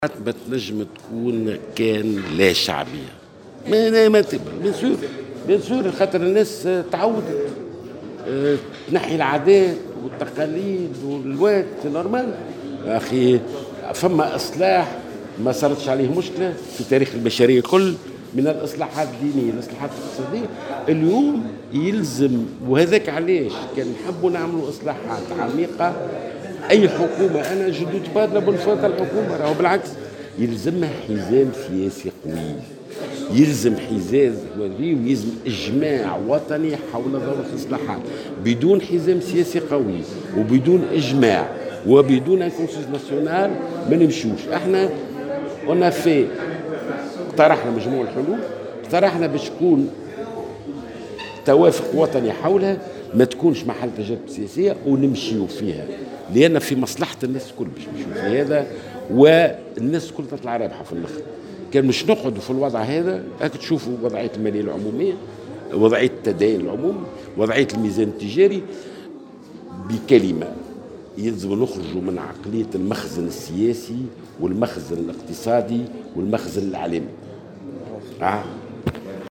أكد رئيس المعهد التونسي للدراسات الإستراتيجية ناجي جلول في تصريح لمراسل الجوهرة "اف ام" اليوم الجمعة على هامش مدونة الإصلاحات الإستراتيجية الكبرى" للمعهد التونسي للدراسات الإستراتيجية أن الإصلاحات لا تكون إلا بحزام سياسي قوي وتشاركي.